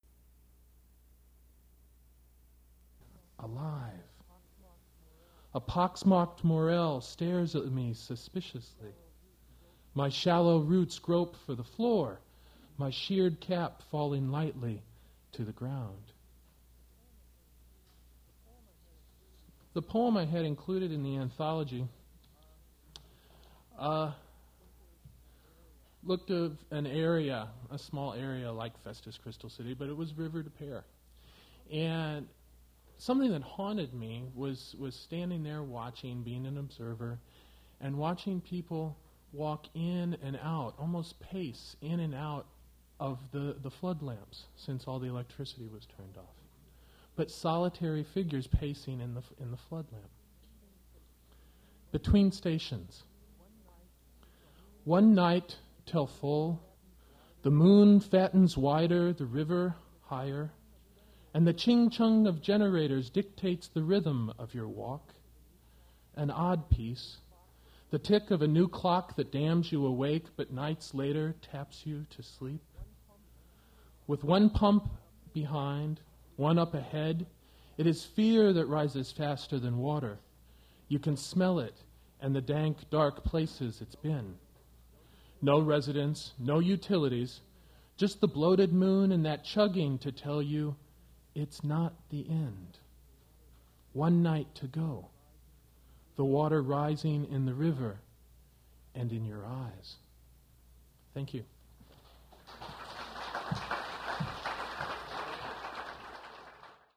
• I think recording starts at the end of a poem, so no introduction; finishes after only one poem